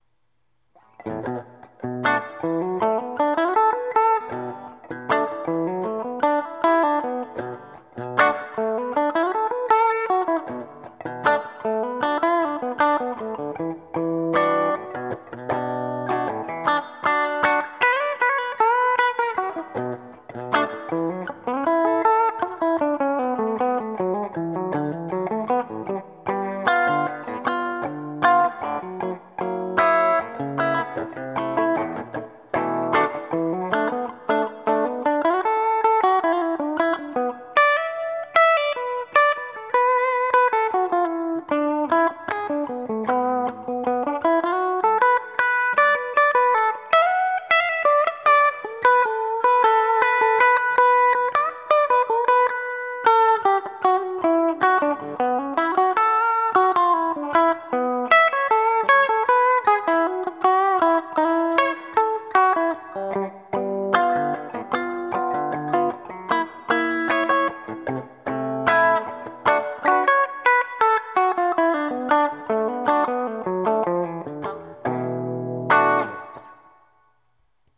The tracks will all be recorded on a Tascam handheld recorder with the built in  MICs @ 1 foot from the speaker.
Demo 2: Old beater electric guitar, small room reverb, lot of treble, bridge pickup and noodling around on B minor. As a side note: I am also using  some compression with the newest opto-compressor described in a previous  post.
bathroom-tile-blues.mp3